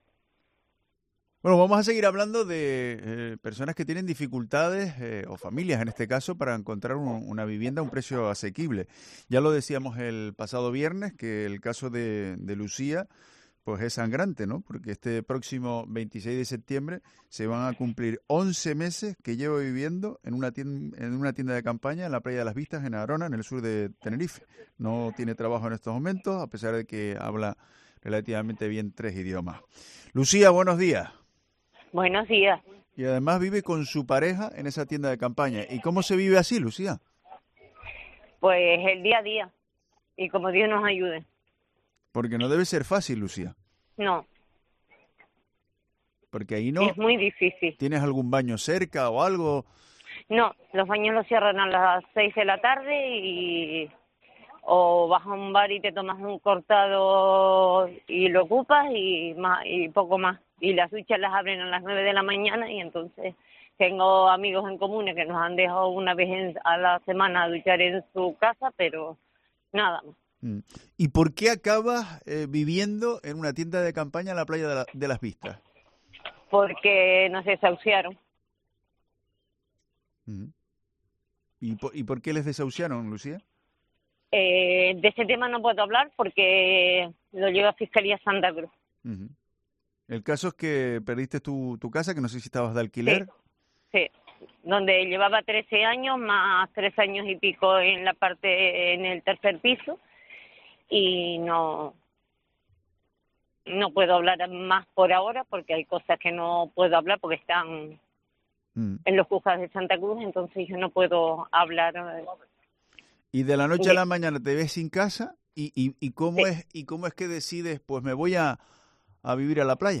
En los micrófonos de Herrera en COPE Canarias hemos seguido analizando los problemas a los que se enfrentan los trabajadores del sector turístico a la hora de alquilar un inmueble en municipios como Adeje, Arona o Guia de Isora.